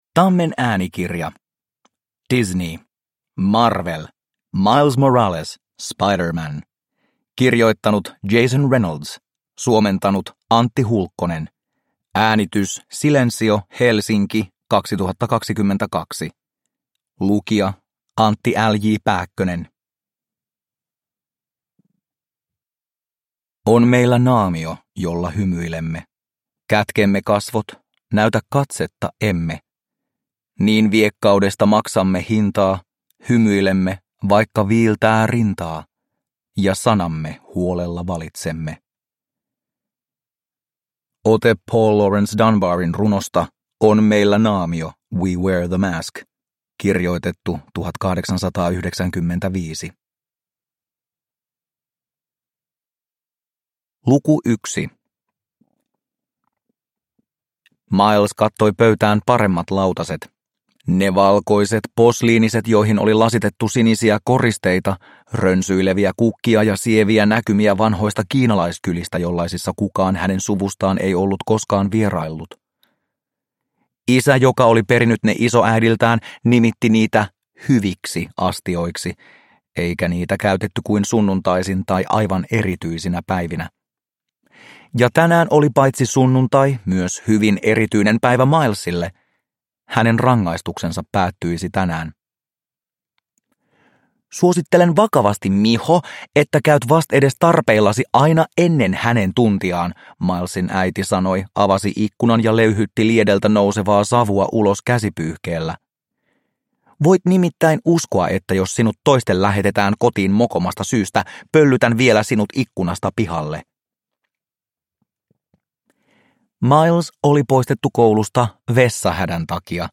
Miles Morales - Spider-Man – Ljudbok – Laddas ner